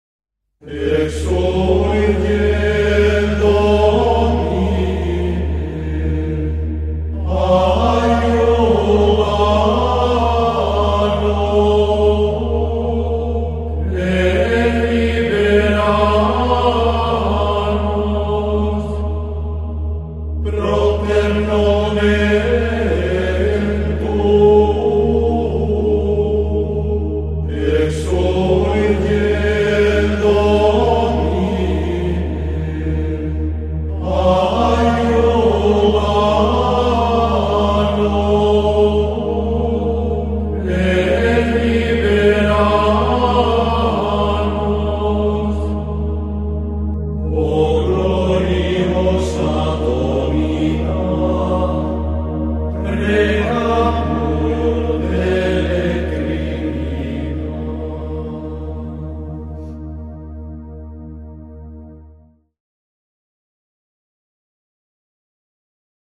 Largo [40-50] mefiance - ensemble instruments - - -